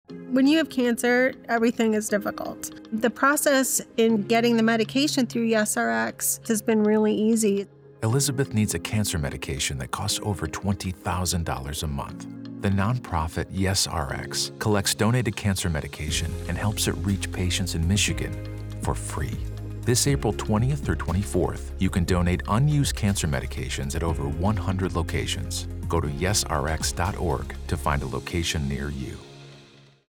public service announcement, to help raise awareness.